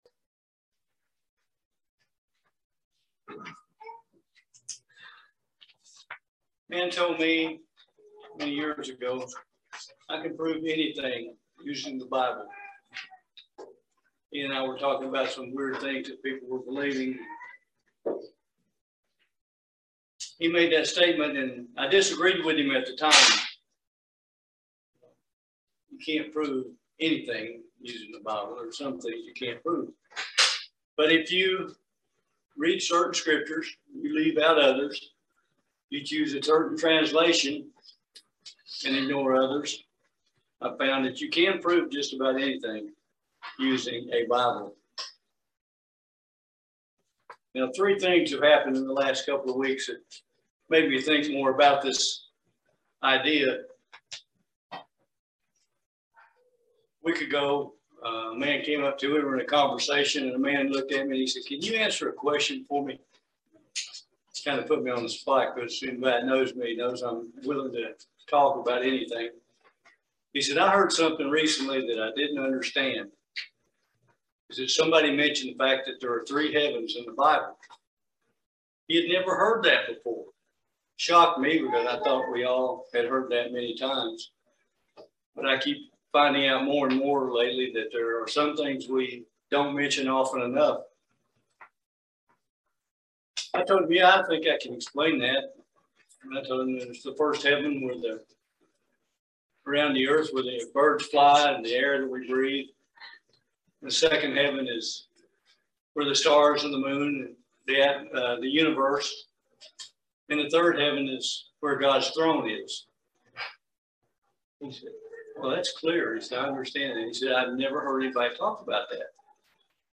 This sermon gives us some reminders and some tools we can use to keep from being deceived.